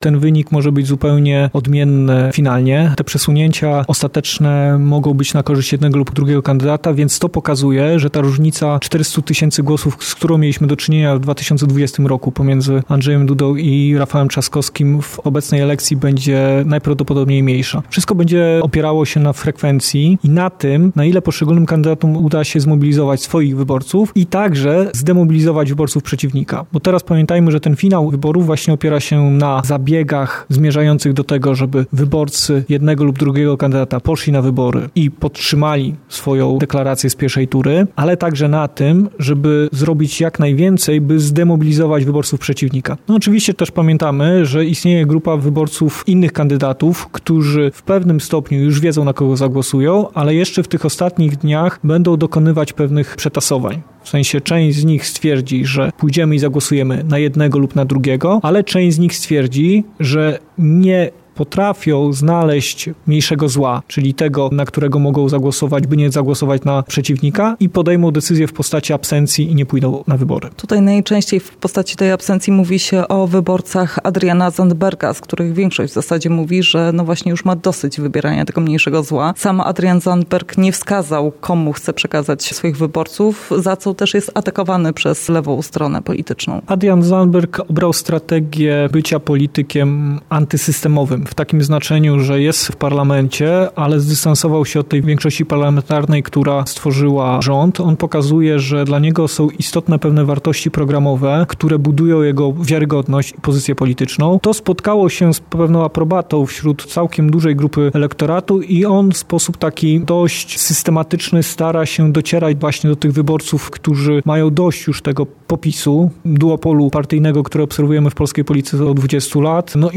Politolog o II turze wyborów prezydenckich: Wszystko będzie opierało się na frekwencji